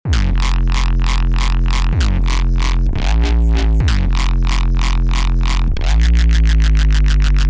BASS HOUSE KITS
A kit dedicated to the old school sound of Bass House! The classic FM wub basses!
Fuel_128 – Bass_Full_2
FUEL_-1-Fuel_128-Bass_Full_2.mp3